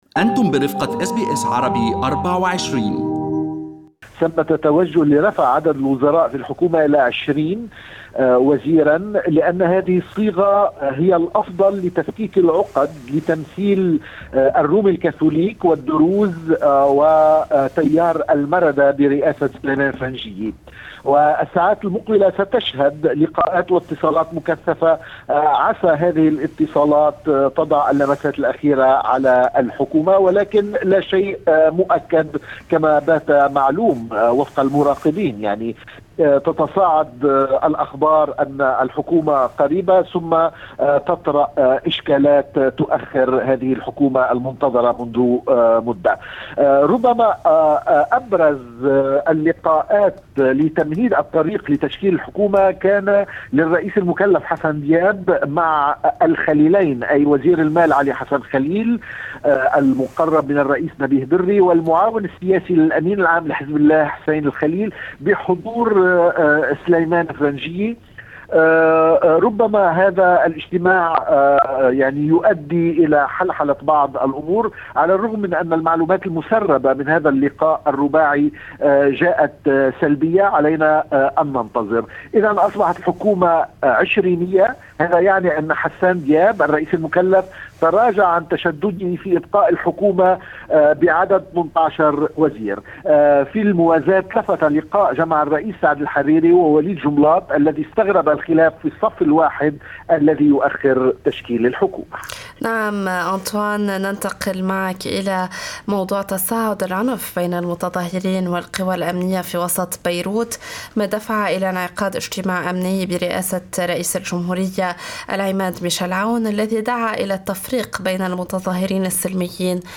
من مراسلينا: أخبار لبنان في أسبوع 21/01/2020